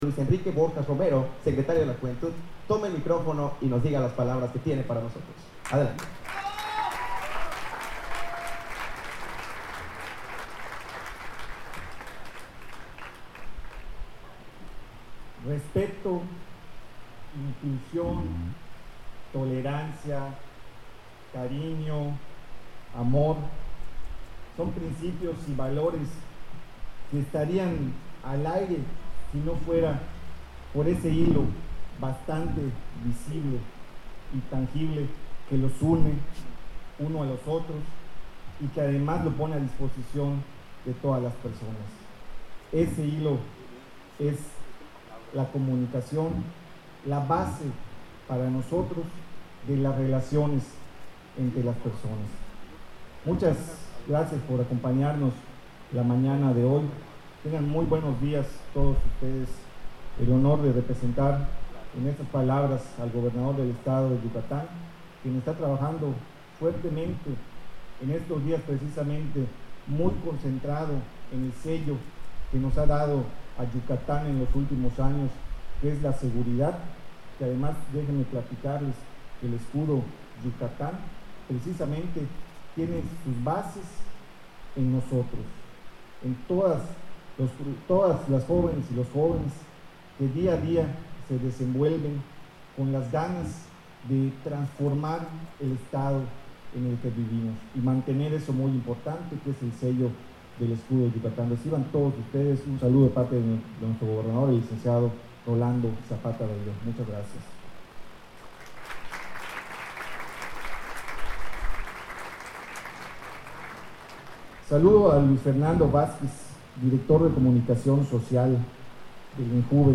PALABRAS-LUIS-BORJAS.mp3